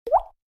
CYPH-Wassertropfen
water-droplet-drip_TzvnBb2.mp3